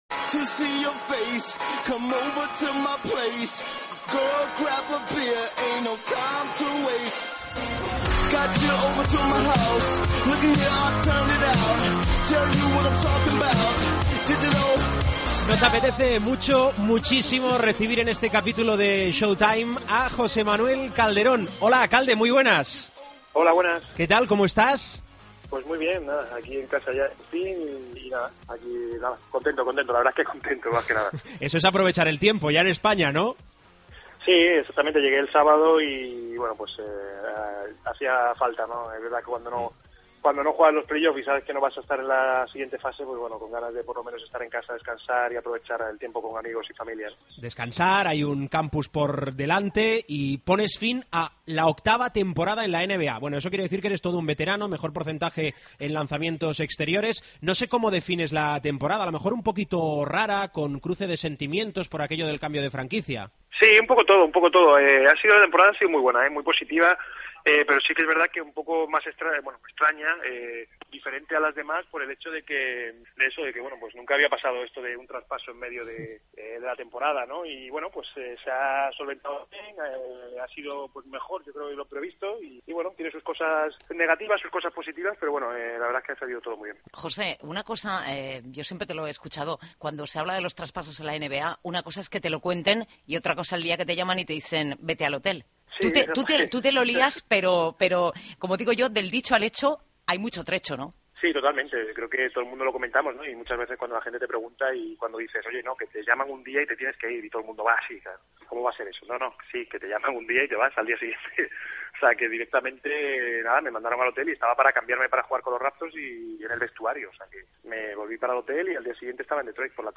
Entrevista a José Manuel Calderón